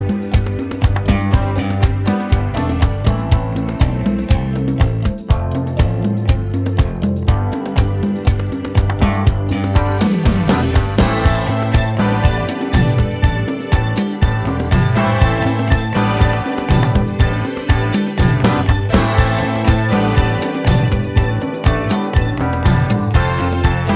No Lead Guitar Pop (1980s) 3:50 Buy £1.50